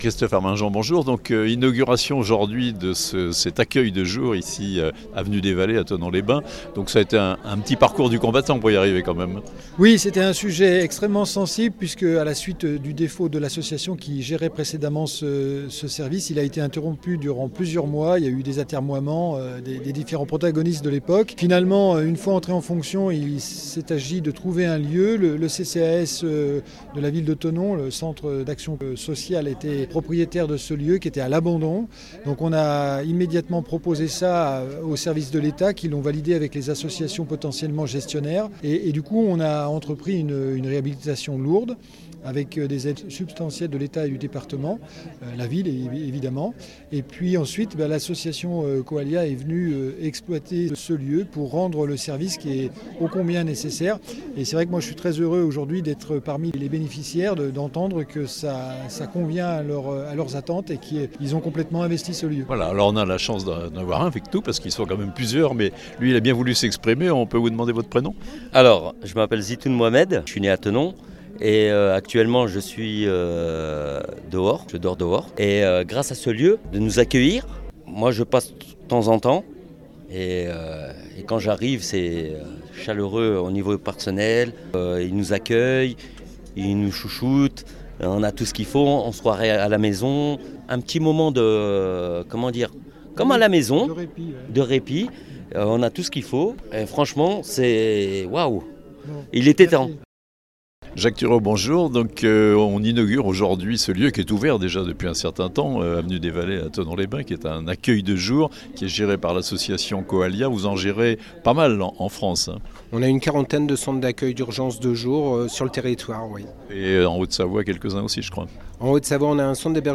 Cet accueil de jour est en fonction depuis la fin de l'année 2022 mais son inauguration officielle s'est déroulée le vendredi 24 février, en présence des représentants des administrations et communes qui ont assuré le financement des travaux de réhabilitation du lieu ainsi que de son fonctionnement quotidien.